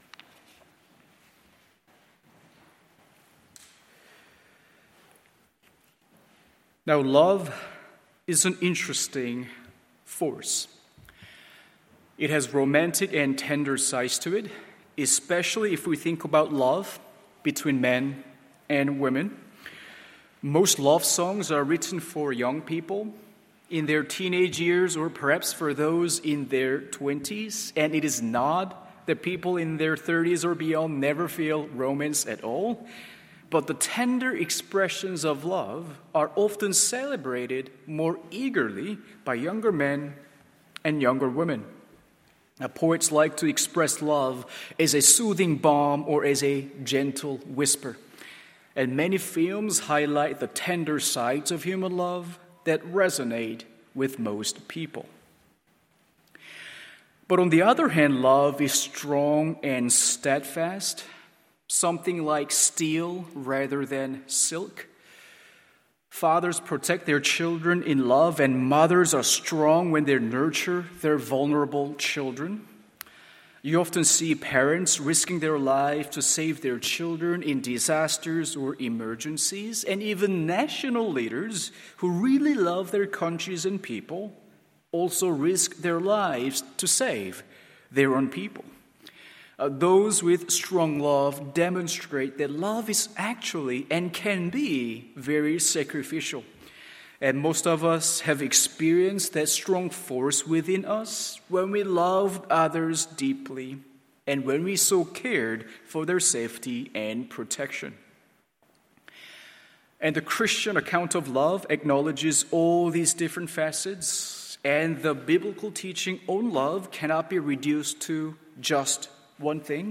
MORNING SERVICE Romans 8:37-39…